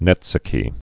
(nĕtsə-kē)